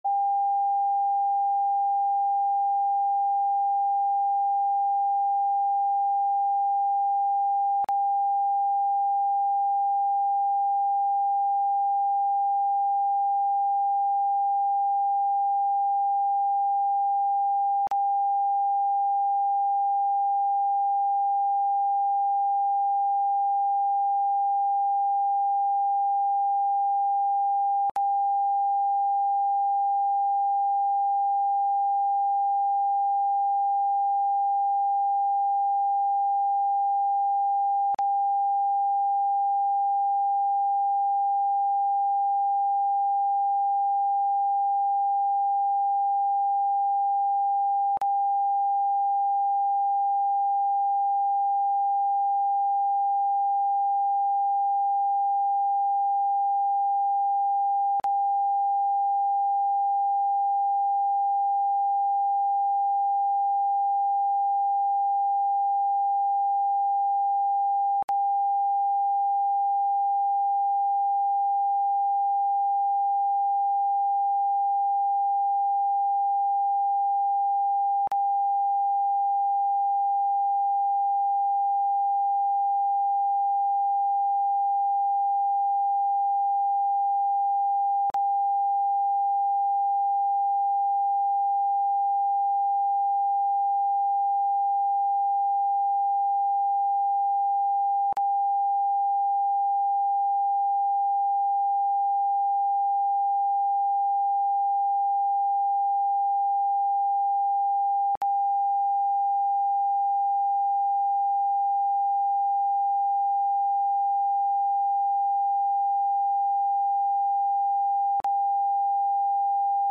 Live and in Technicolor on the first Saturday of every month from 11am-12pm